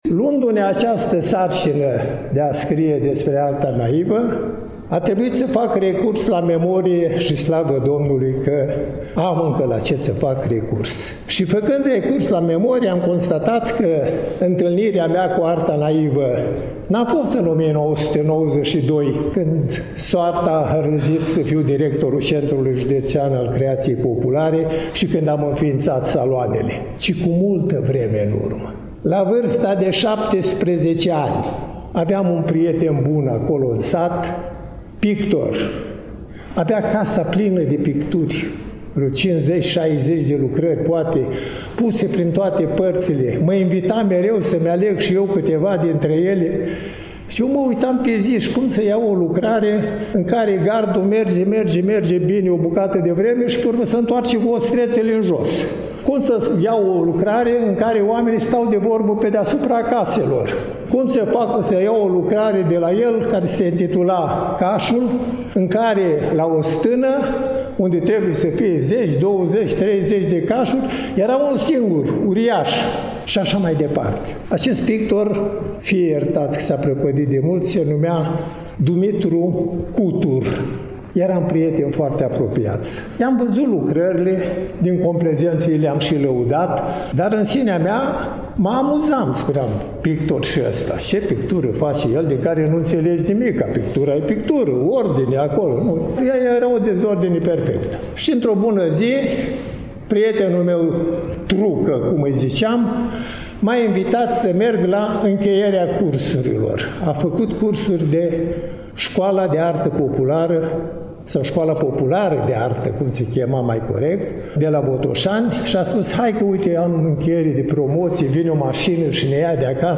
Evenimentul s-a desfășurat, la Iași, în ziua de vineri, 25 aprilie 2025, începând cu ora 14, în incinta Muzeului Etnografic al Moldovei, Complexul Muzeal Național „Moldova”.